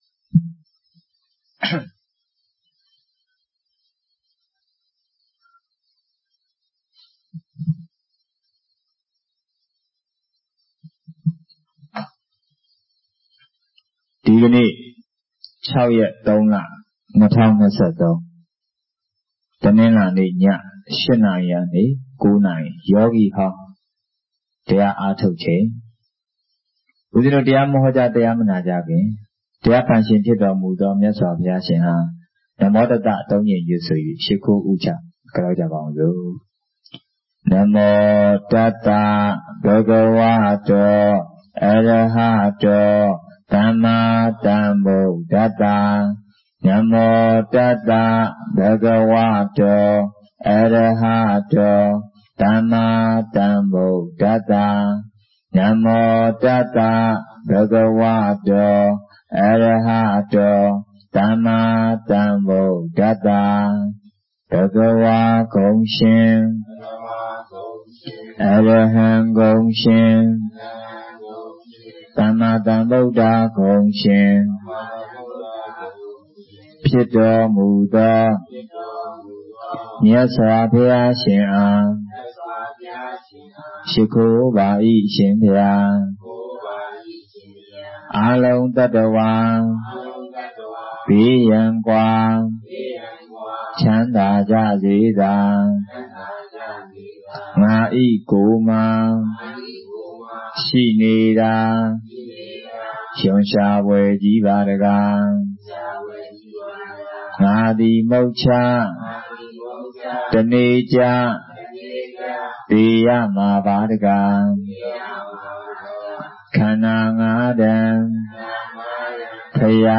Mar6_2023_ ညစဉ်တရားပွဲ ကမဋ္ဌန်းအသစ် 2 အမေးအဖြေ